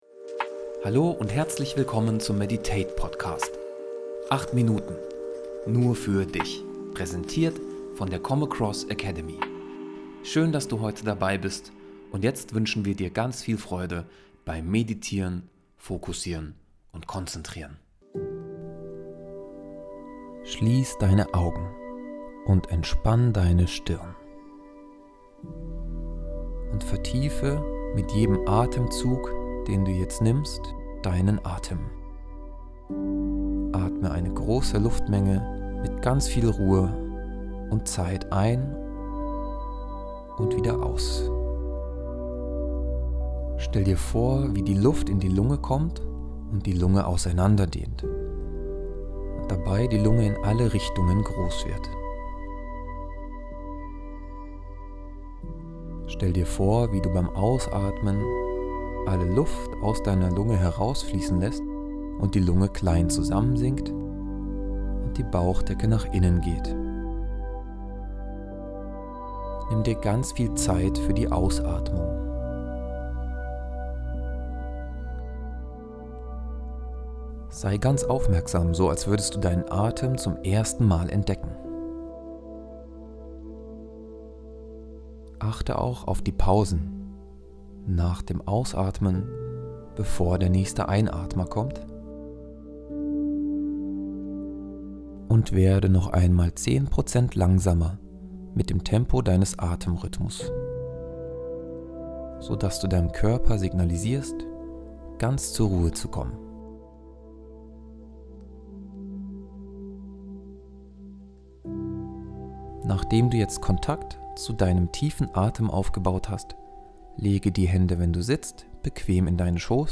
Progressive Muskelentspannung [Meditation]